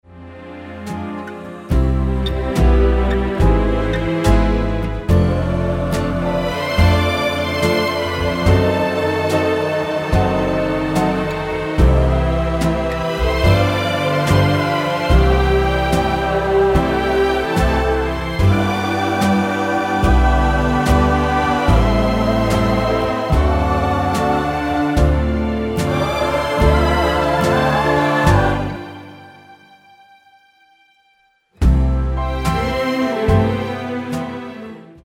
Tonart:C mit Chor
Die besten Playbacks Instrumentals und Karaoke Versionen .